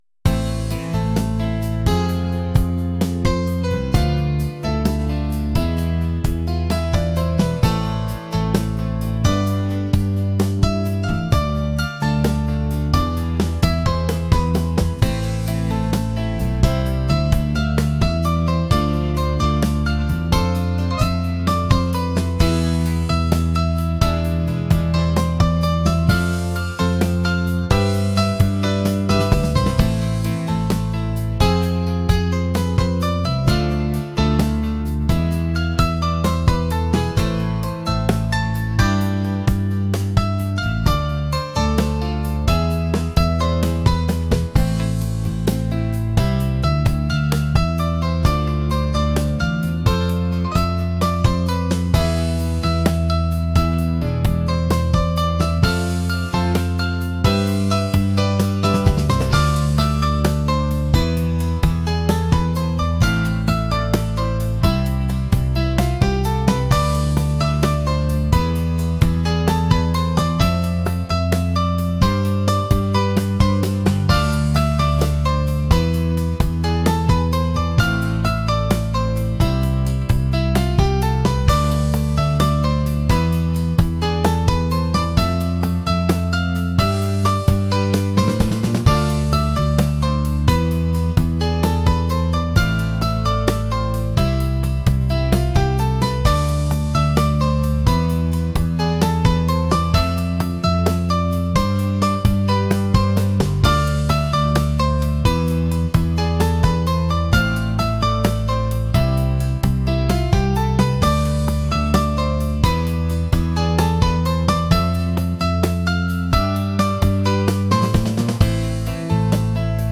シリアス